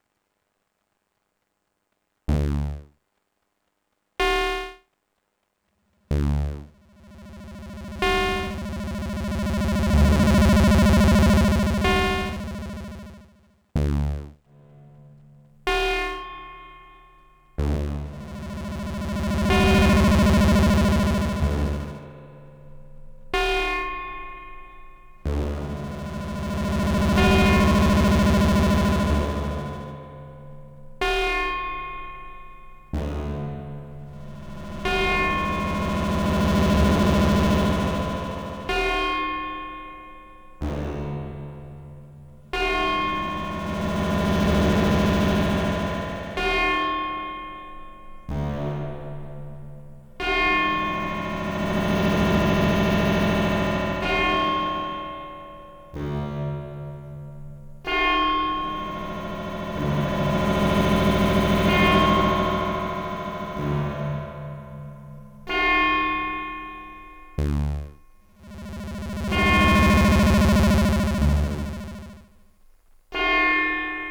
Here is a pair of sound clips comparing the 190 with 2 different sized reverb tanks, using only 100 series modules:
Sound Sample with 1AB2A1B "medium" tanks.